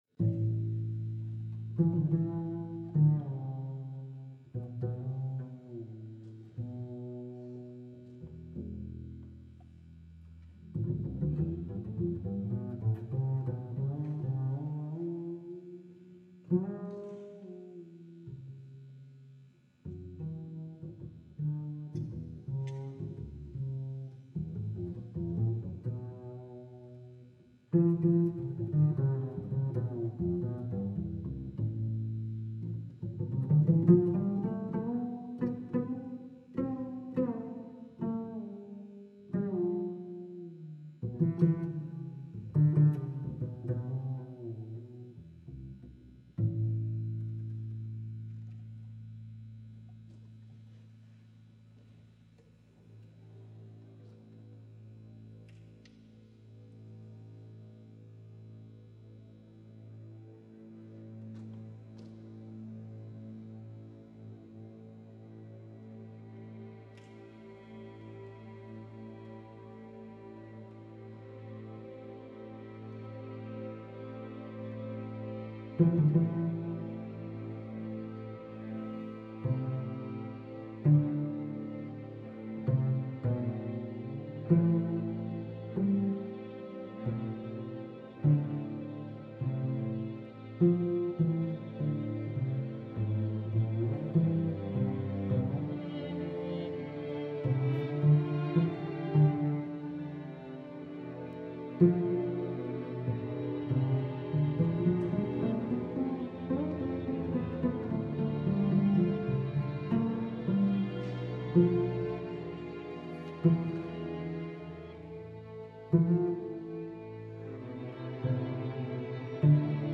MUSICA PER STRUMENTO SOLISTA E ORCHESTRA
per contrabbasso amplificato e archi